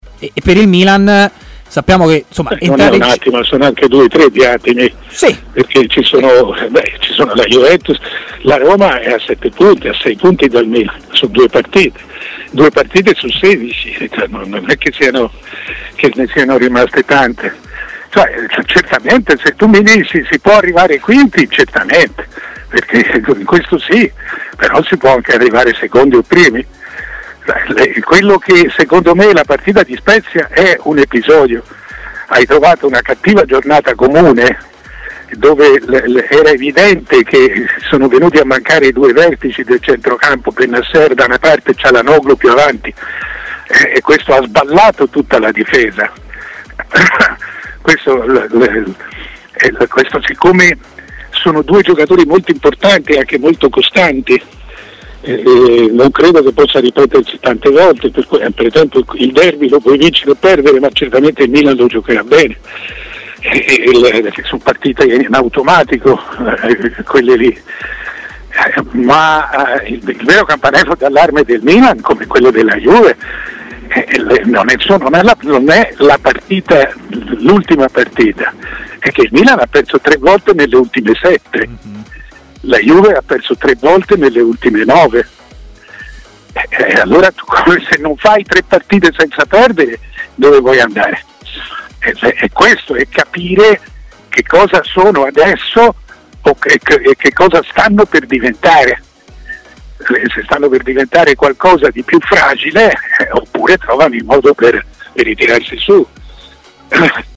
Mario Sconcerti è intervenuto in diretta a TMW Radio, nel corso della trasmissione Stadio Aperto.